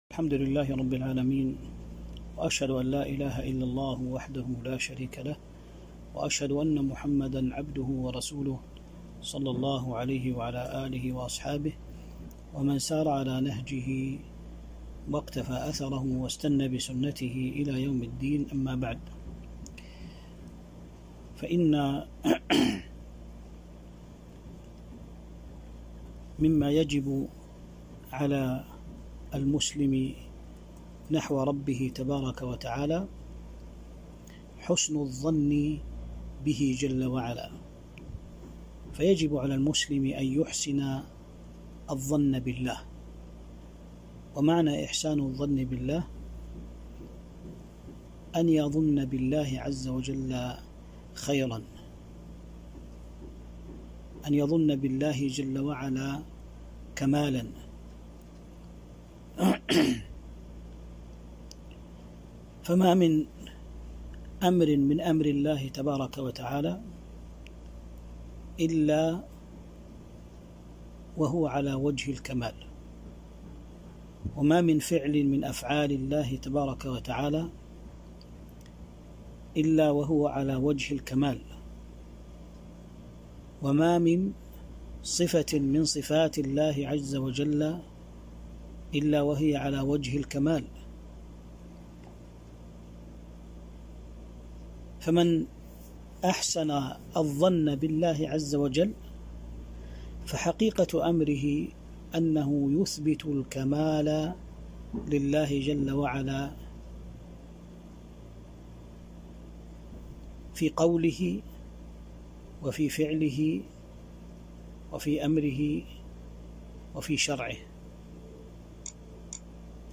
محاضرة قيمة شرح حديث أنا عند ظن عبدي بي